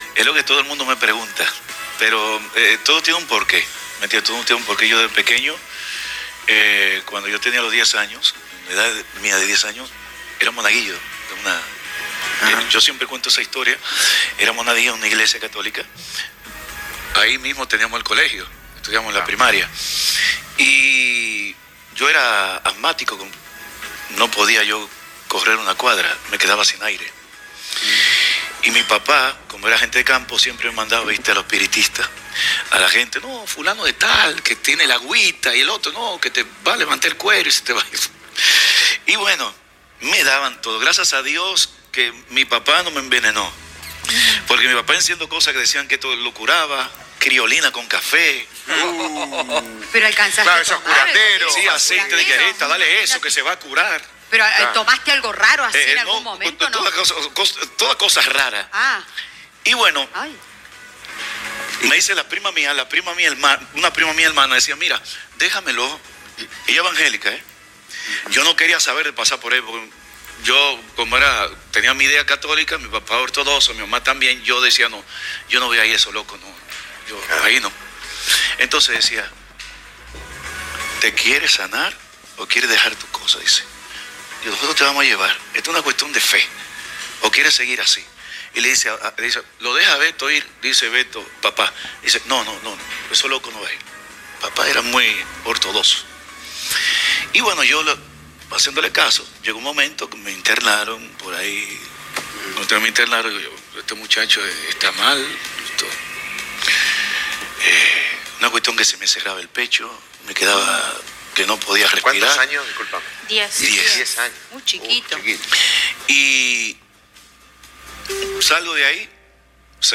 Invitado al programa Quién dice Qué, Jean Carlos contó muchas cosas sobre su vida, su arte y su vigencia. Pero cómo fue su encuentro con la fe, fue uno de los momentos más emotivos.